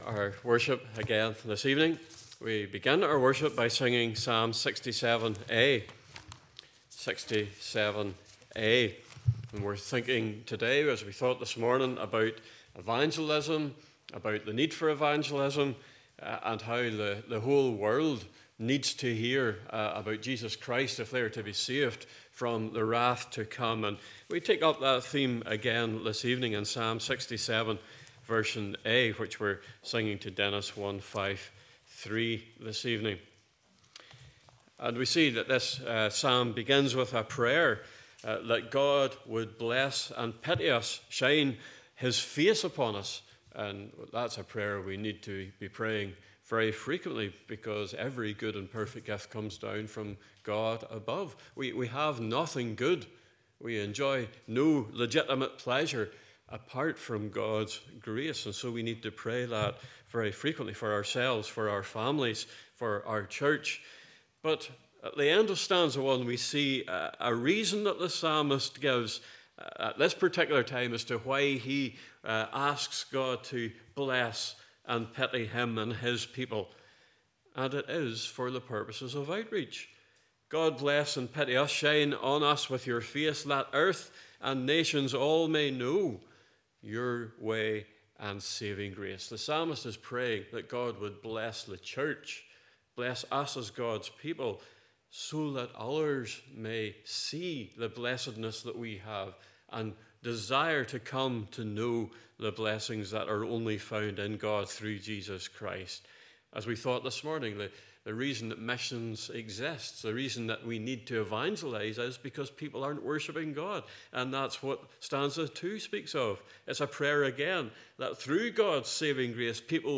Passage: Jonah 3 : 1 - 10, & Matthew 12 : 38 - 41 Service Type: Evening Service